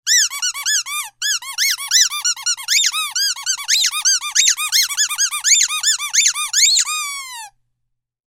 Dog-toy-noise.mp3